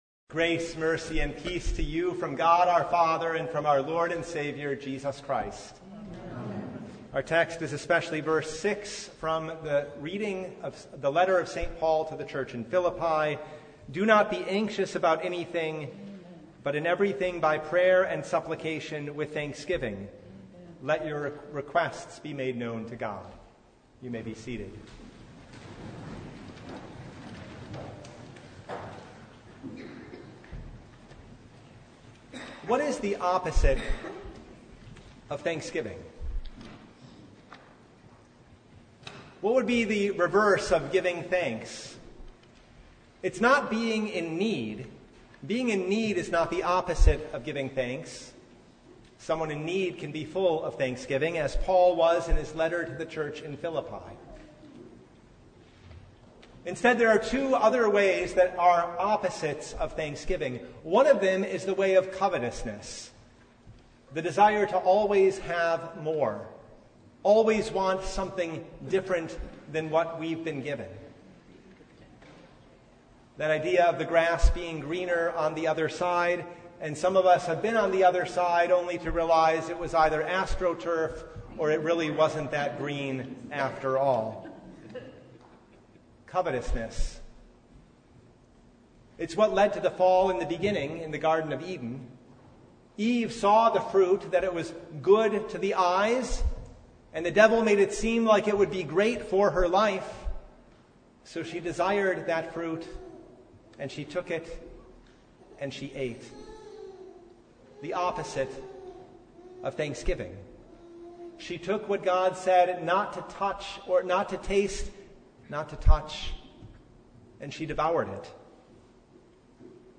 Service Type: Thanksgiving Eve